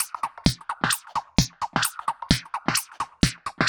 Index of /musicradar/uk-garage-samples/130bpm Lines n Loops/Beats